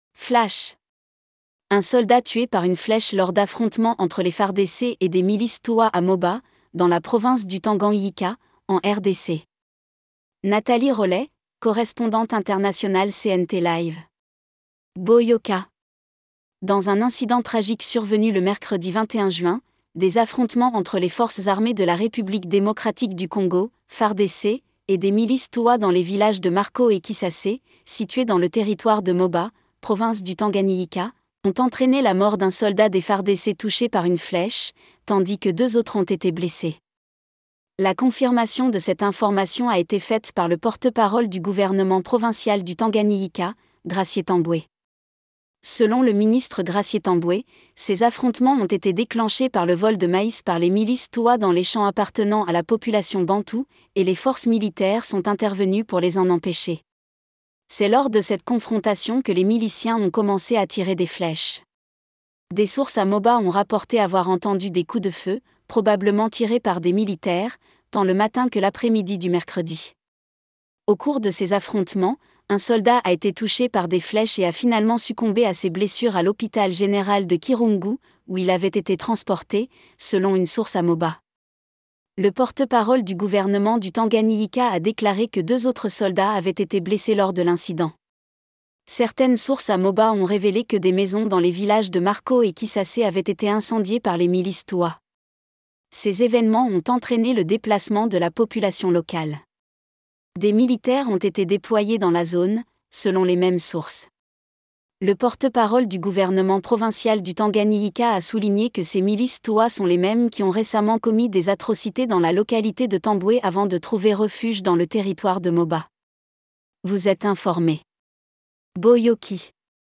LISTEN TO THE NEWS HERE - ECOUTEZ Un soldat tué par une flèche lors d'affrontements entre les FARDC et des milices touwa à Moba, dans la province du TanganIyika, en RDC.